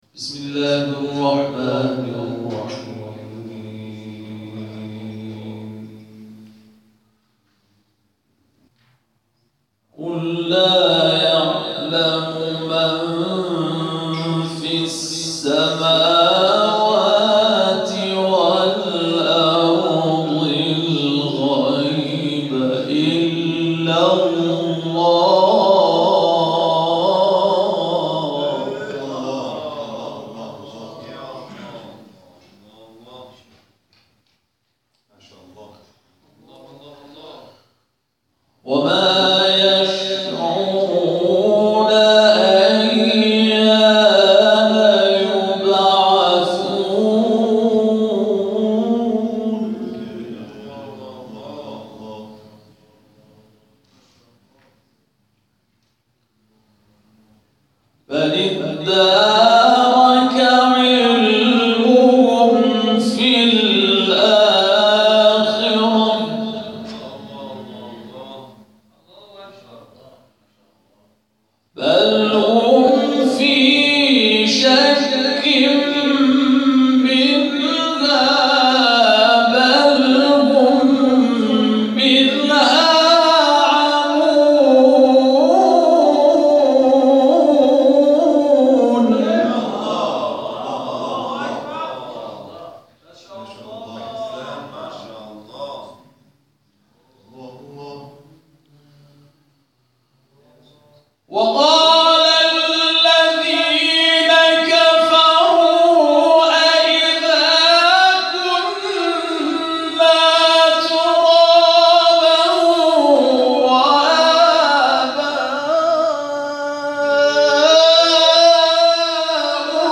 در ادامه تلاوت‌های این مراسم ارائه می‌شود.
تلاوت